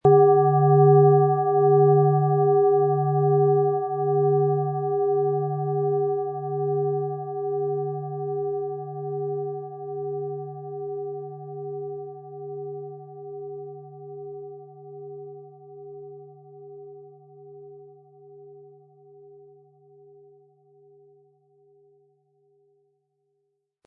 Von Hand getriebene Klangschale mit dem Planetenklang Mars aus einer kleinen traditionellen Manufaktur.
Den passenden Klöppel erhalten Sie umsonst mitgeliefert, er lässt die Schale voll und wohltuend klingen.
MaterialBronze